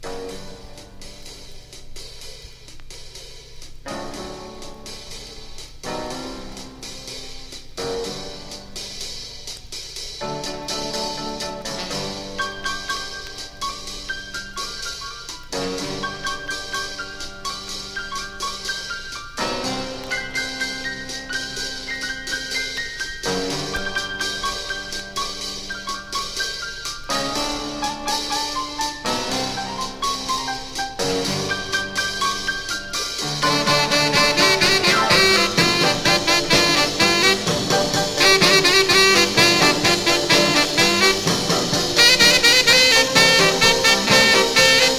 Rock’N’Roll, Garage Rock　USA　12inchレコード　33rpm　Mono